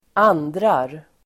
Uttal: [²'an:dra:r]